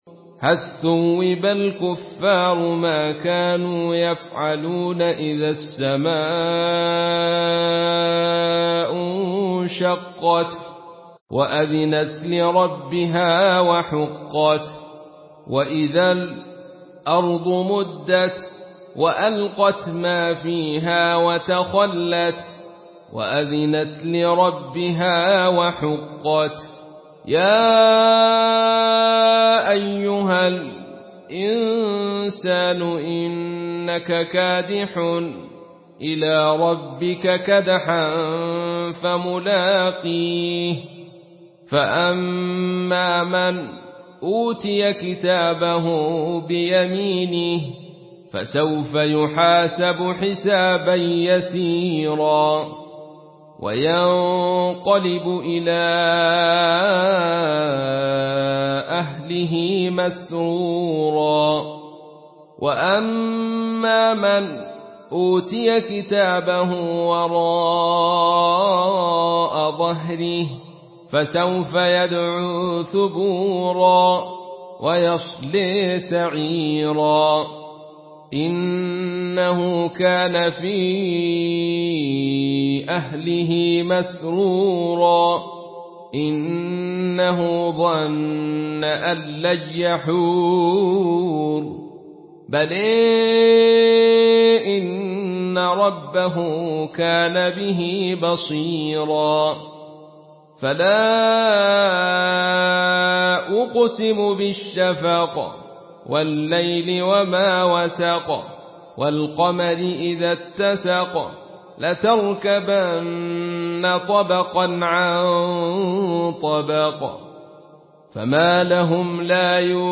Surah Inşikak MP3 in the Voice of Abdul Rashid Sufi in Khalaf Narration
Surah Inşikak MP3 by Abdul Rashid Sufi in Khalaf An Hamza narration.
Murattal